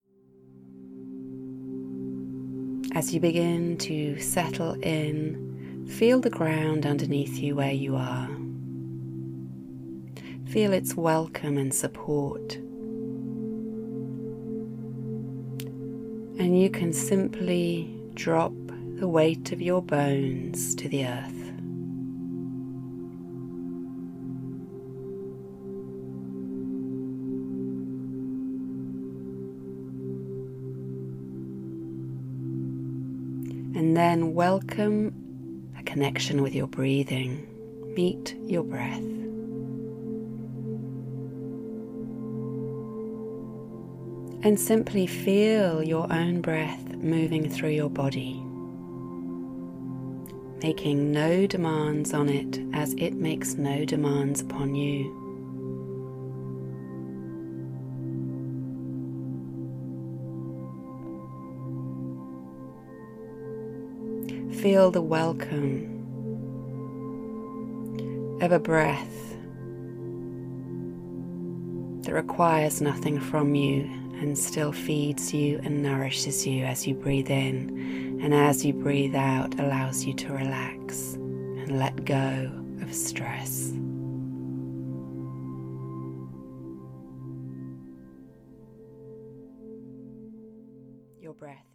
The album contains six guided meditations, each from 16 to 24 minutes long, plus a brief recording on how to use the meditations.